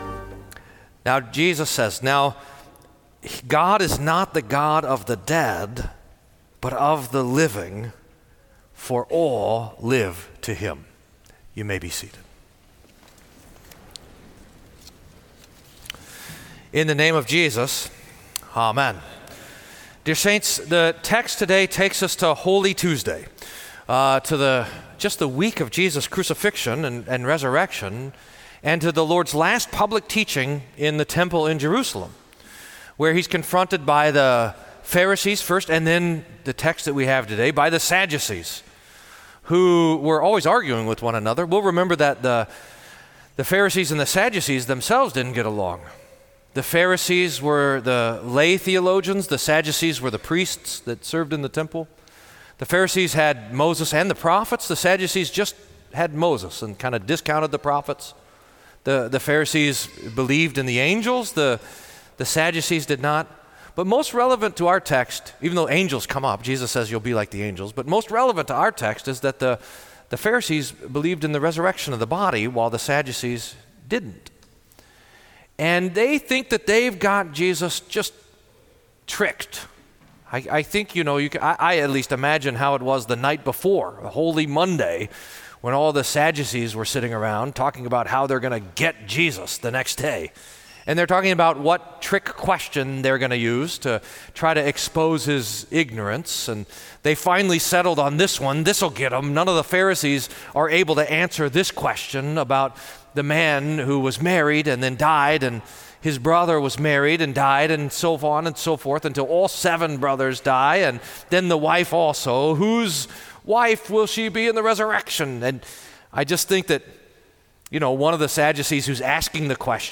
Sermon for Twenty-second Sunday after Pentecost